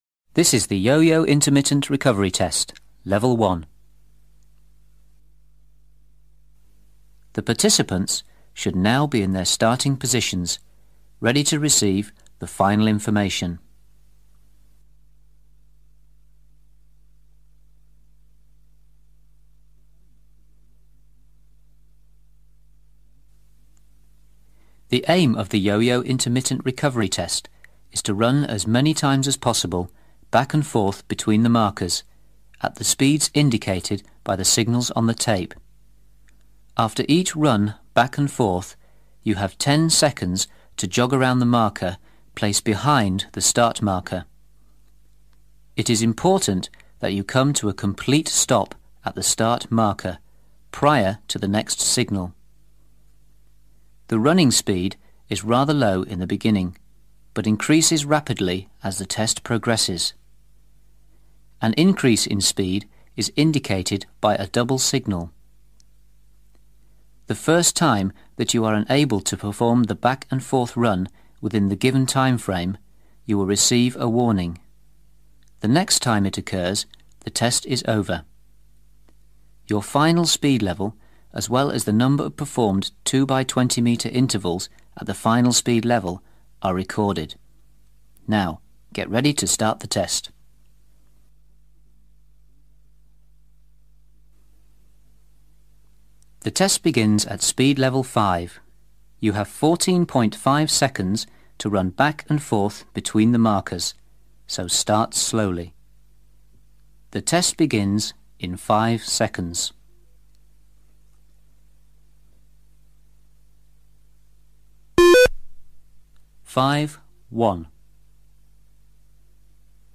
Rozhodcovia musia dokončiť nasledujúcu sekvenciu v súlade s tempom určeným zvukovou nahrávkou: beh 20 m (B-C), otočka a beh 20m (C-B) chôdza 5 m (B-A), otočka a chôdza 5 m (A-B) Zvuková nahrávka Yo-Yo Intermittent Recovery testu – úroveň 1 (Yo-Yo Prerušovaný test) určuje tempo behu a dĺžku odpočinku. Rozhodcovia musia držať krok so zvukovou nahrávkou, kým nedosiahnu odporúčanú úroveň.